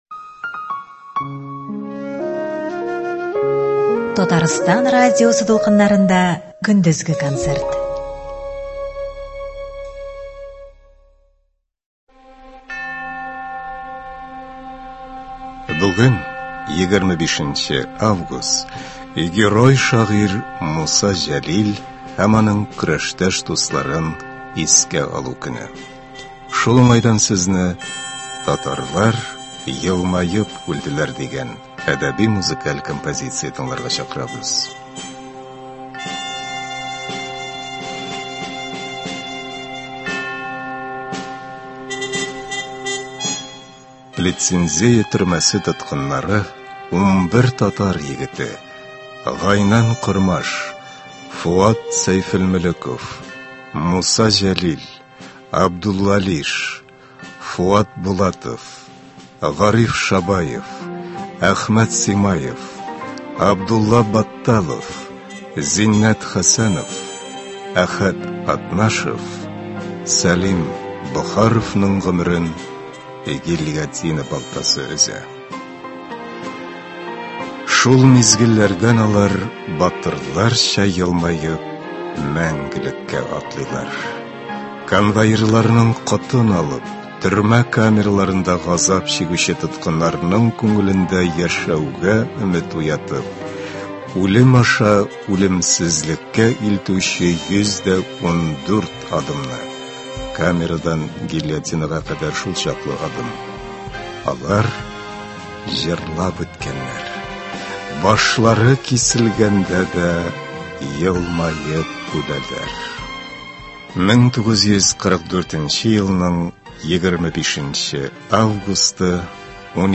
Бүген, 25 август, каһарман шагыйребез Муса Җәлилнең һәм аның какшамас рухлы батыр көрәштәшләренең якты рухларын искә алу көне. Шул уңайдан игътибарыгызга «Батырлар елмаеп үлделәр» дип исемләнгән әдәби-мәзыкаль композиция тәкъдим ителә.